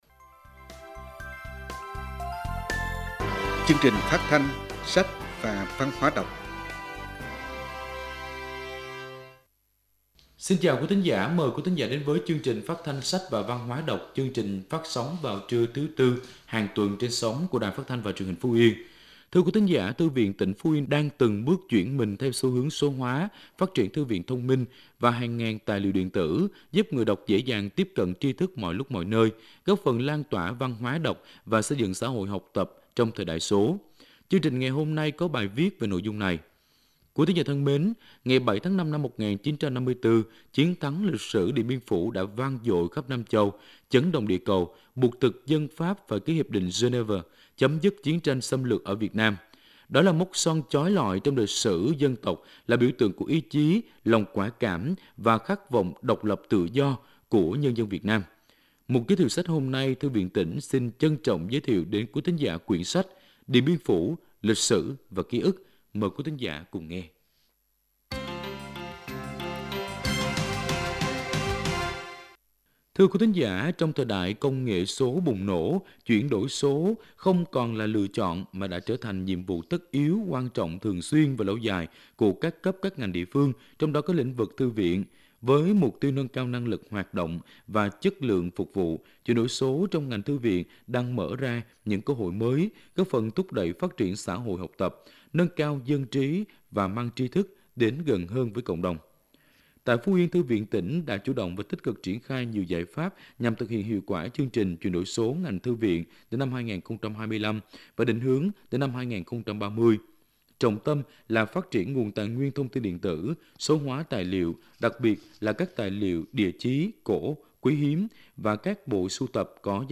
Chương trình phát thanh “Sách và Văn hóa đọc”: Phát sóng trưa thứ 4 ngày 07/5/2025
Chương trình được phát sóng vào trưa thứ Tư hàng tuần trên sóng của Đài Phát thanh và Truyền hình Phú Yên.